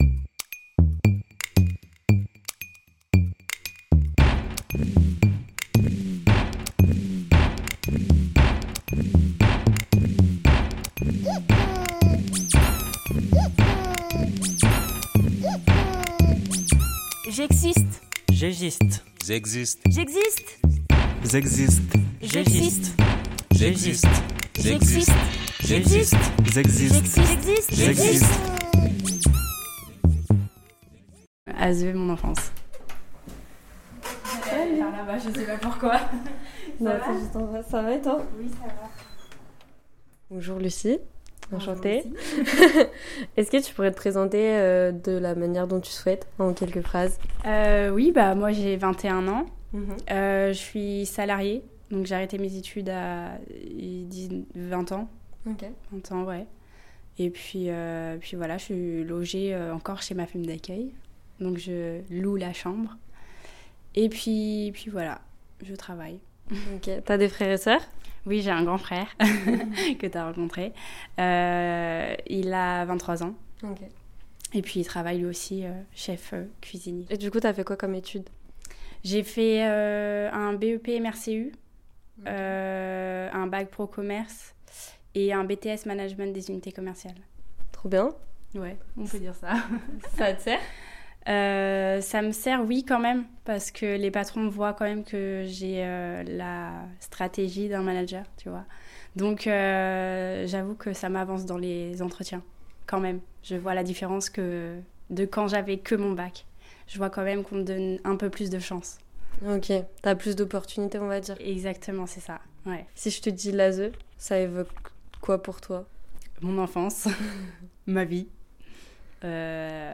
Jingle réalisé par les adhérents de l’ADEPAPE Repairs ! 44